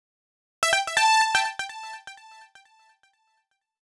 XTRA037_VOCAL_125_A_SC3.wav